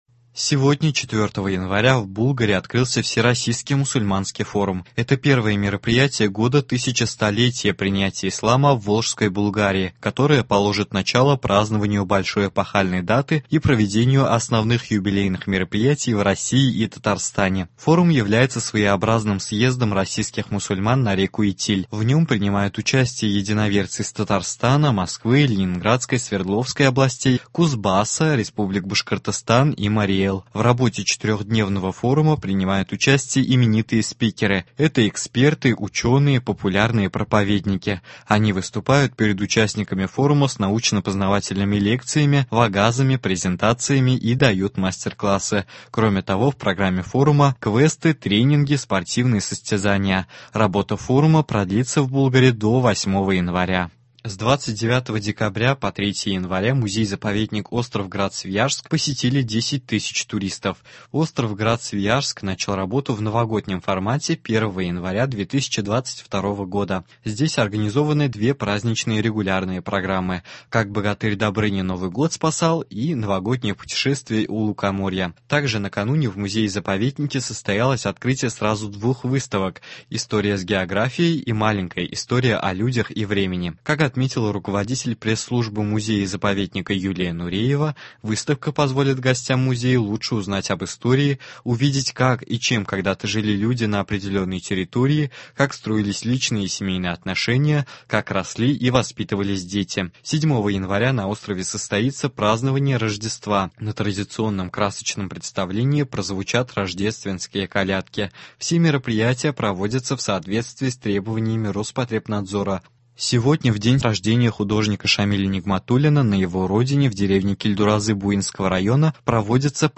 Новости (04.01.22) | Вести Татарстан